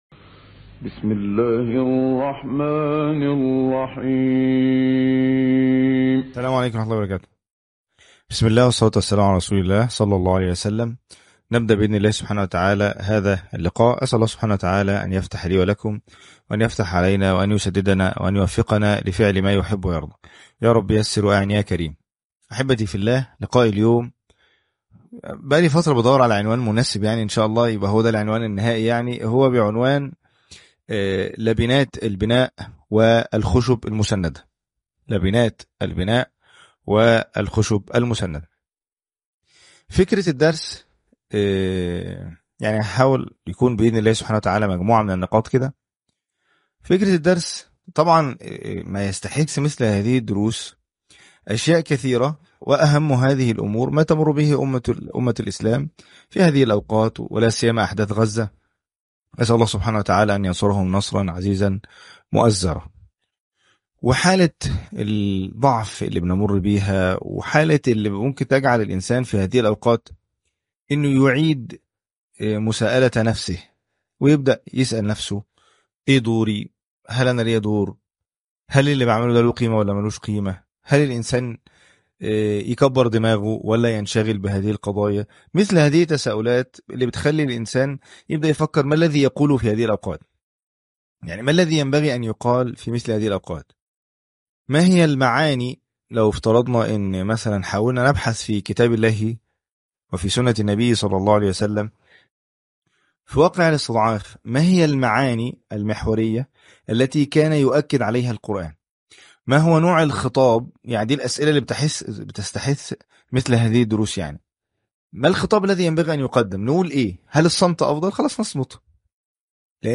(37) لبنات البناء والخشب المسندة - أمسية تربوية